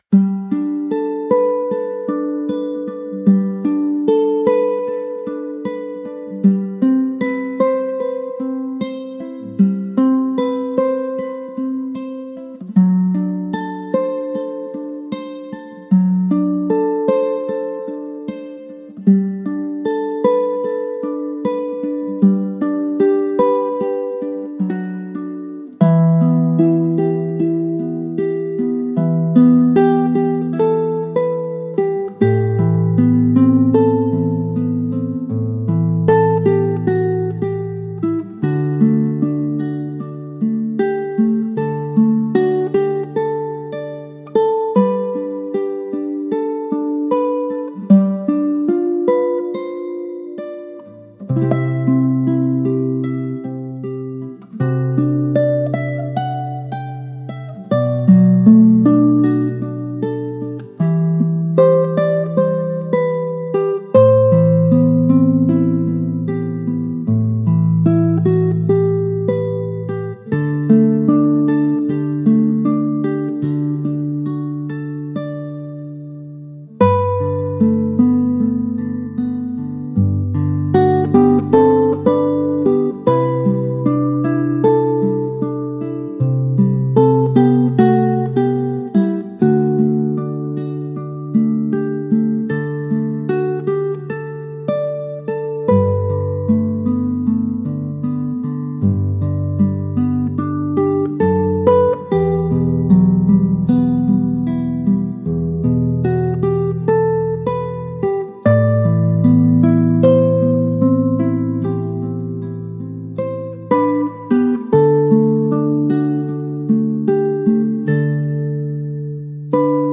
Колыбельные песни для